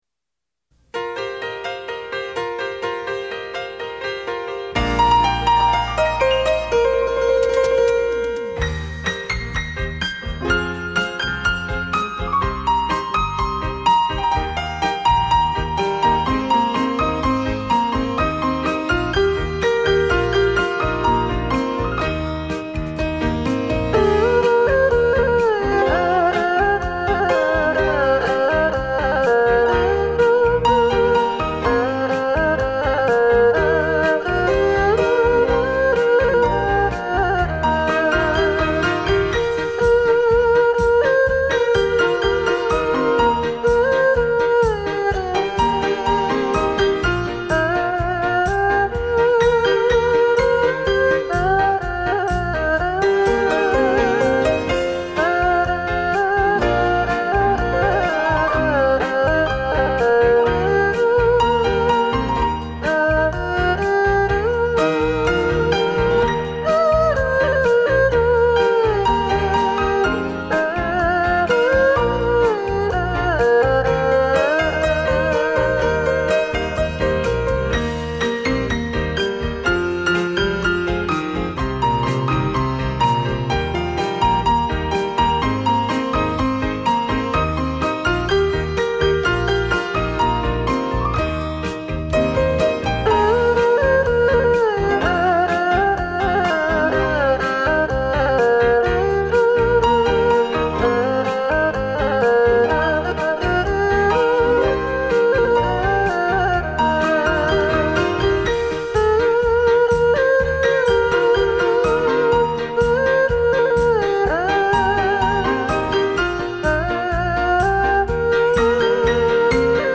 二胡演奏
国际级二胡演奏大师
徐徐丝竹声 依如走入幽幽小径